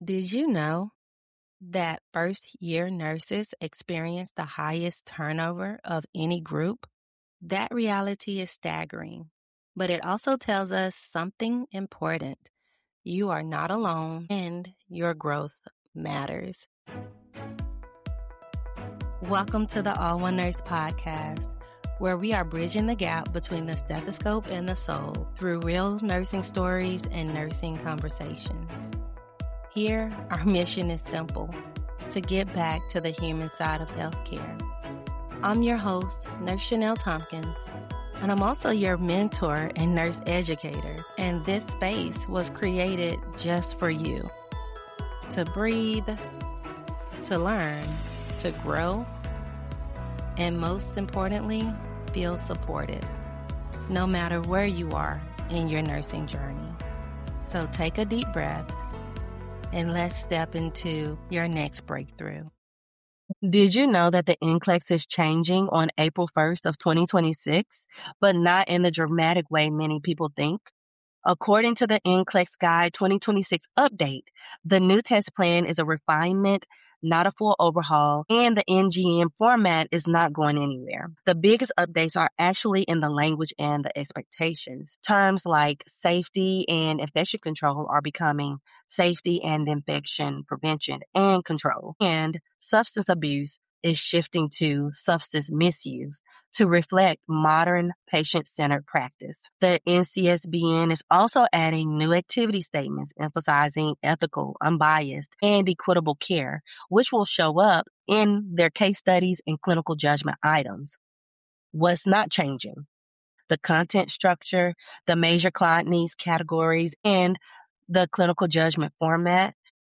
This solo episode provides essential insights for new nurses navigating the pre-orientation phase, working full shifts while studying for the NCLEX, and maintaining character and faith during this challenging time.